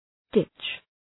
Shkrimi fonetik {dıtʃ}